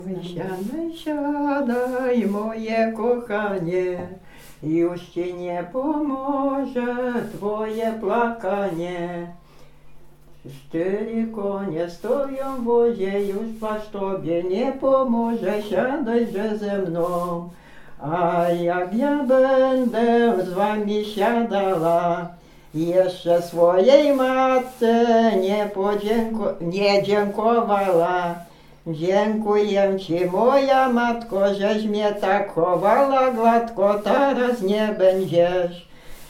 Weselna
migracje przesiedleńcy weselne na wyjazd do kościoła wesele